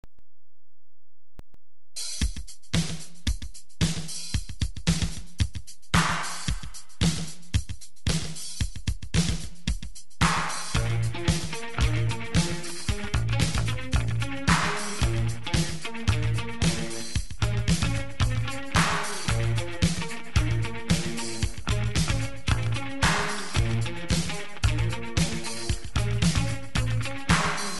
エフェクター処理をして
お手軽 リミックス 改造曲 に作り変える事など お手のもの。